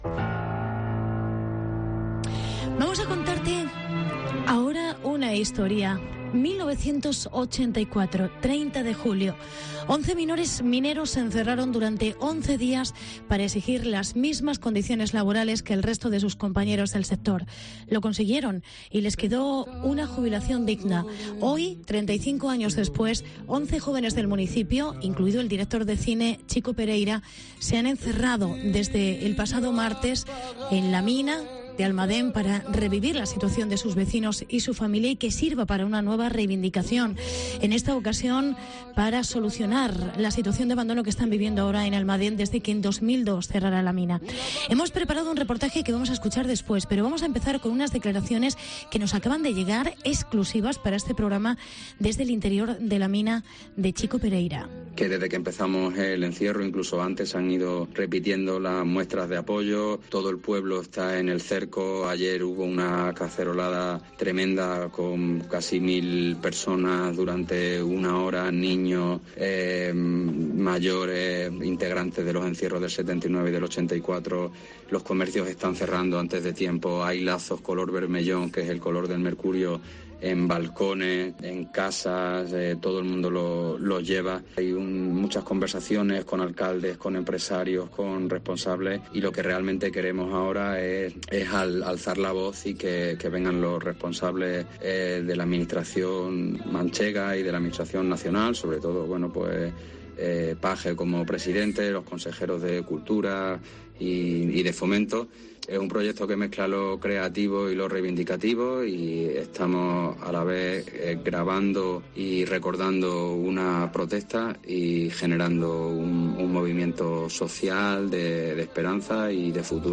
Hablamos con los 11 encerrados en las minas de Almadén después de 4 días.